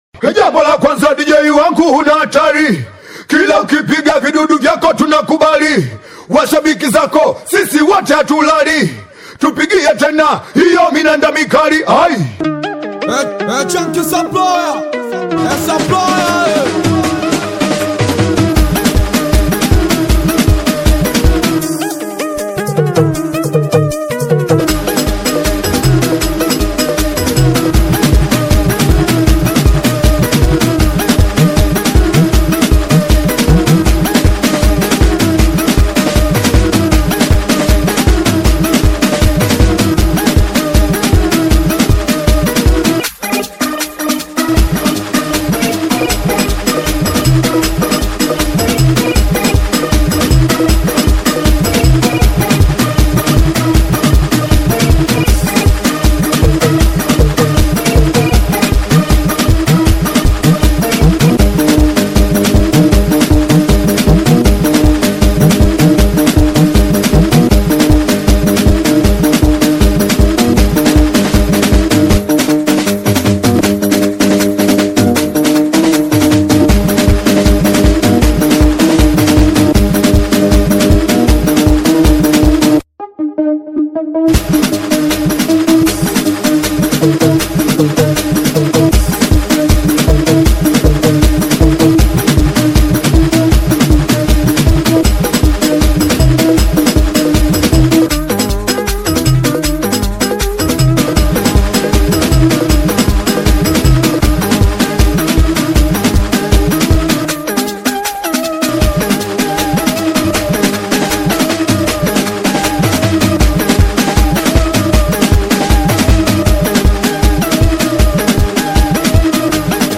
BITI SINGELI • SINGELI BEAT